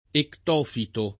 [ ekt 0 fito ]